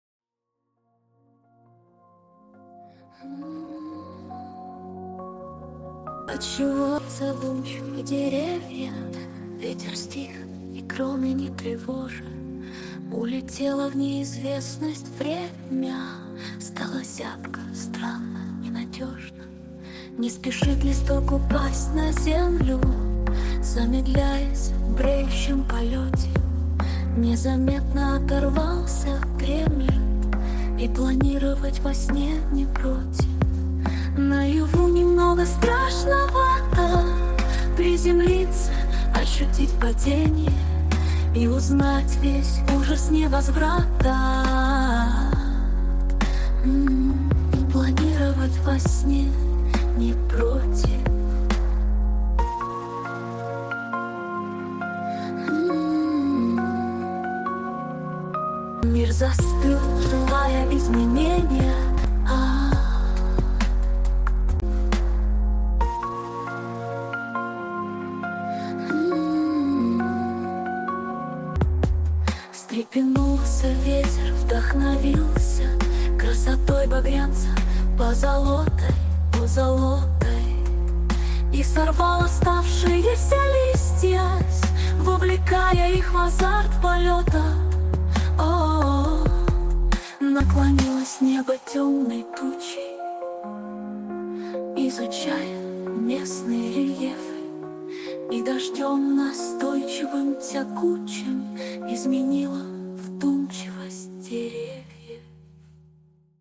mp3,2531k] Авторская песня